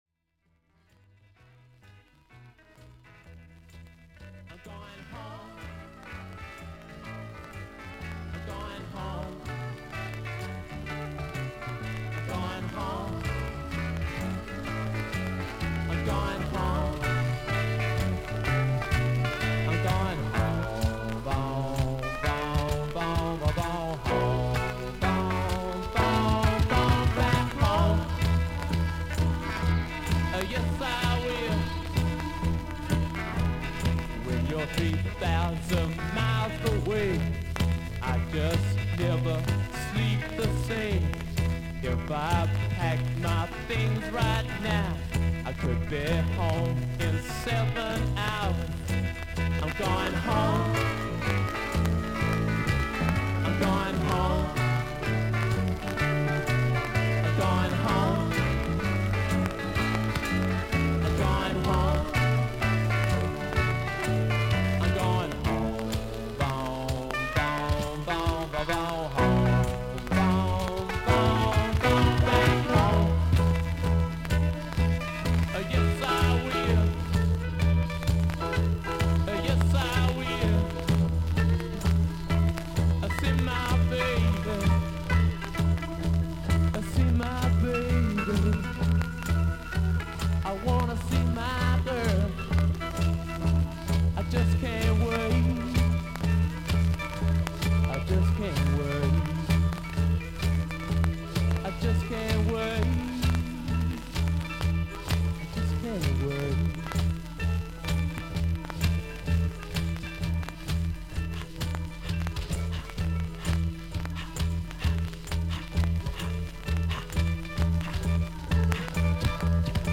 少々サーフィス・ノイズあり。音はクリアです。